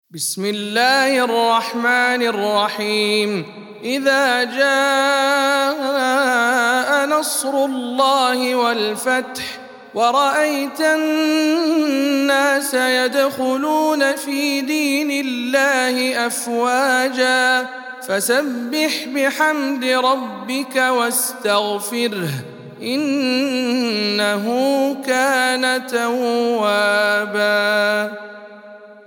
سورة النصر - رواية هشام عن ابن عامر